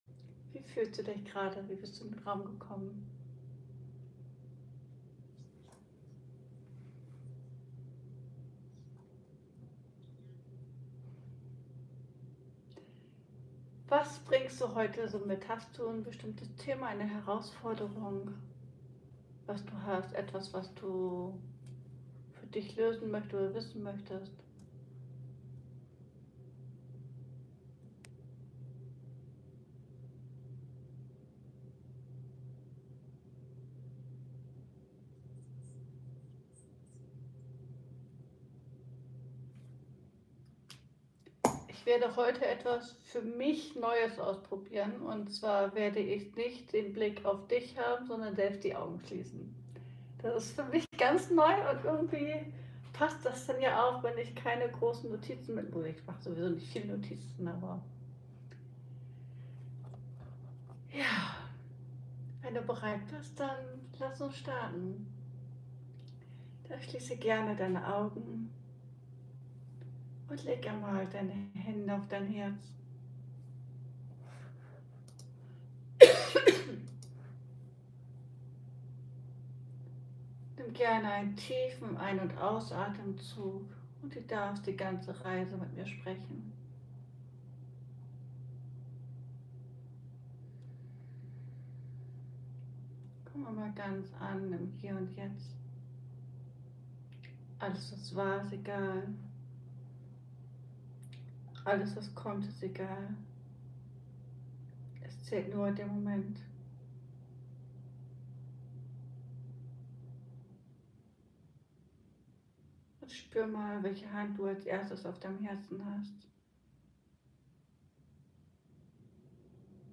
Livemitschnitt - Geboren um zu Leben ~ Ankommen lassen Podcast